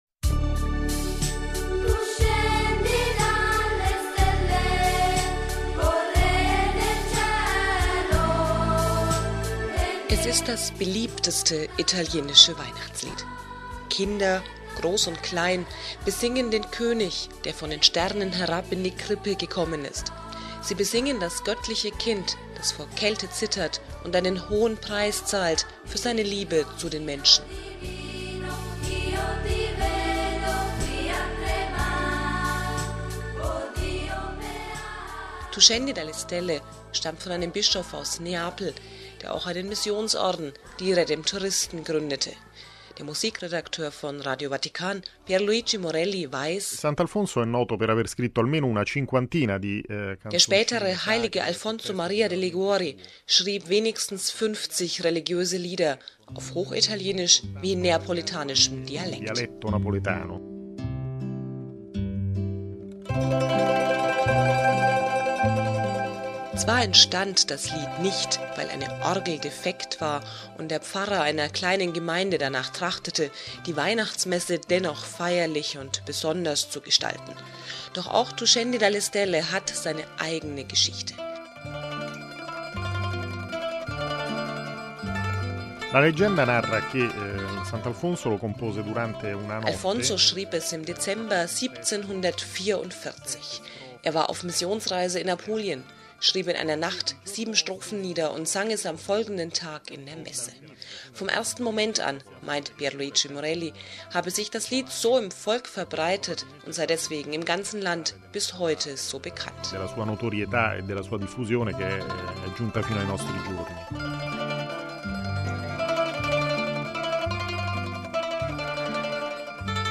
„Tu scendi dalle stelle“ hat seinen Platz am Ende der Christmette, Kinderchöre, Knabenchöre und viele mehr singen diese einfachen Zeilen vor der Krippe, ganz als ob sie dieses Kindlein in den Schlaf wiegen wollten.